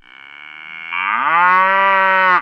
COW.WAV